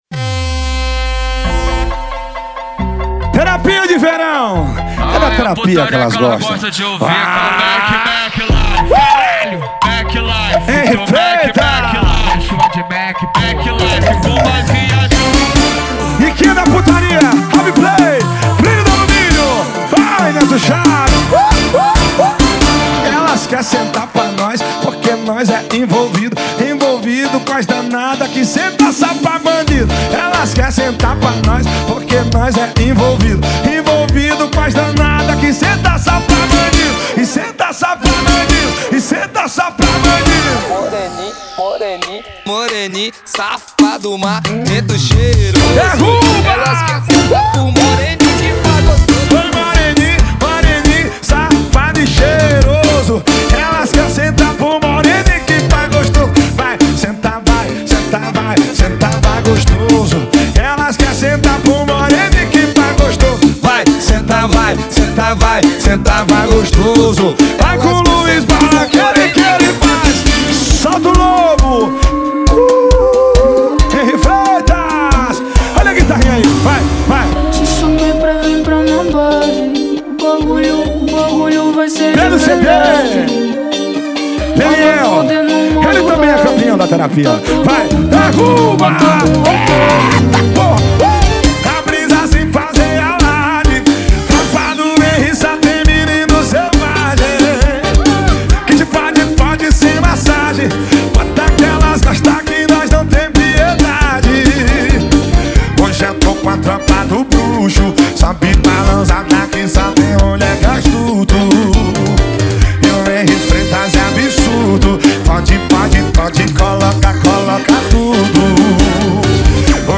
2024-02-14 18:31:09 Gênero: Forró Views